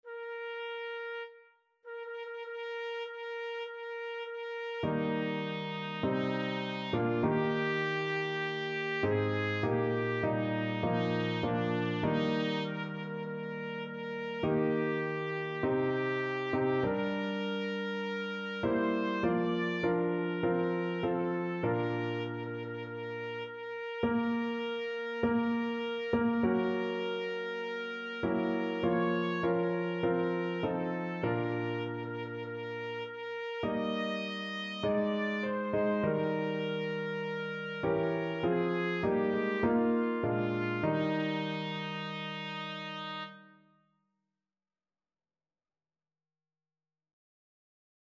4/4 (View more 4/4 Music)
Classical (View more Classical Trumpet Music)